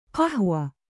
音标：qahwah